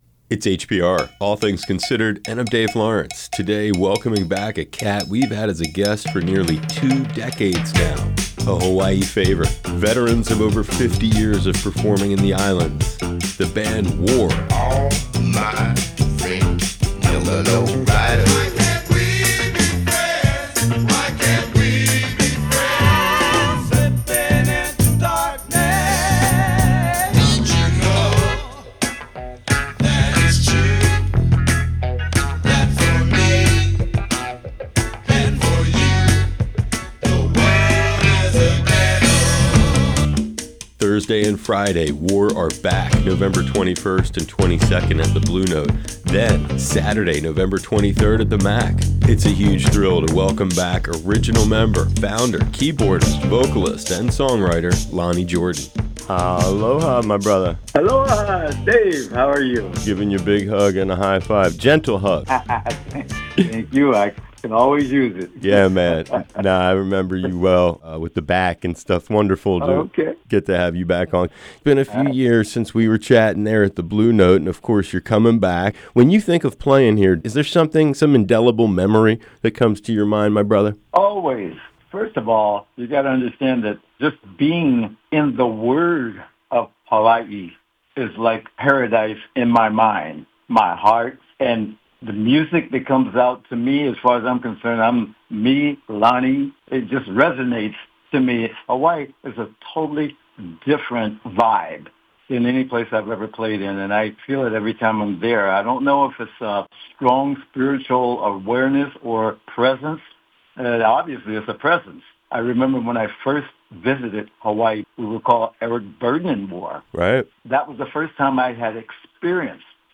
war-lonnie-jordan-2024-interview-feature.mp3